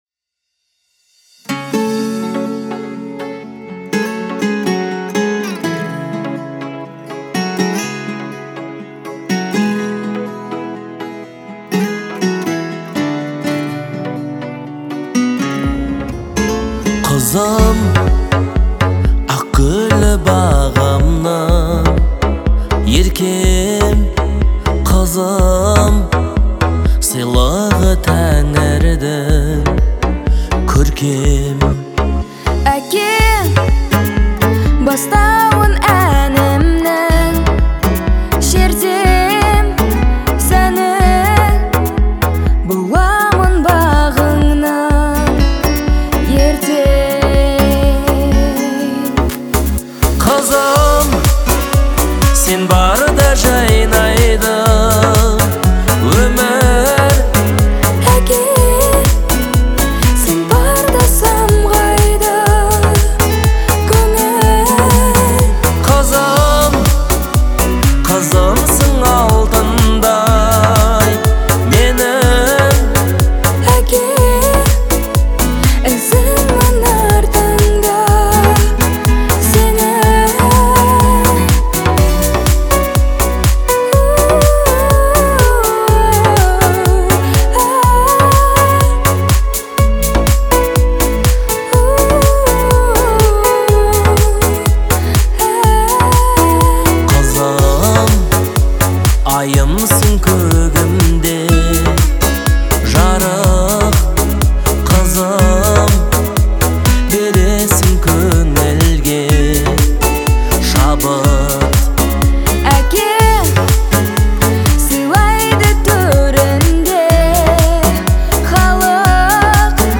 это яркая композиция в жанре казахской поп-музыки